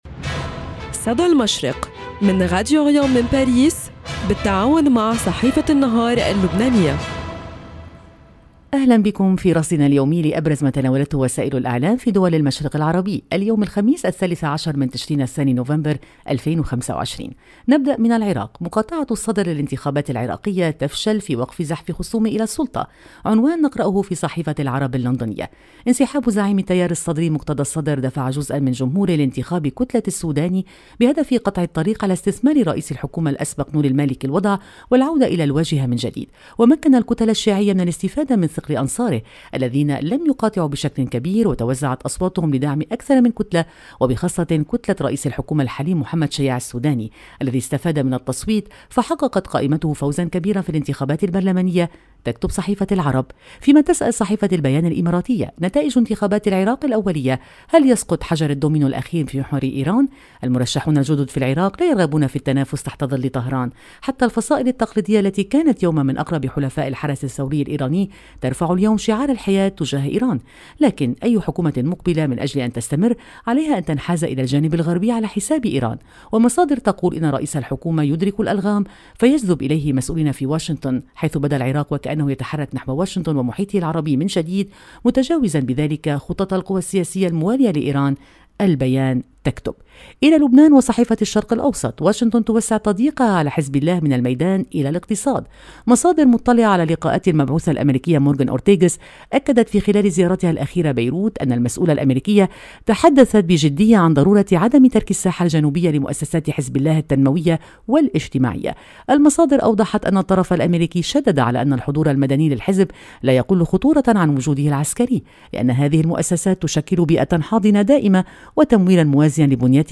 صدى المشرق – نافذتكم اليومية على إعلام الشرق، كل صباح في تعاون بين راديو أوريان إذاعة الشرق من باريس مع جريدة النهار اللبنانية، نستعرض فيها أبرز ما جاء في صحف ومواقع الشرق الأوسط والخليج العربي من تحليلات مواقف وأخبار،  لنرصد لكم نبض المنطقة ونحلل المشهد الإعلامي اليومي.